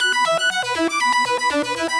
SaS_Arp03_120-E.wav